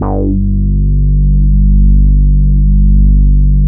22 BASS 2.wav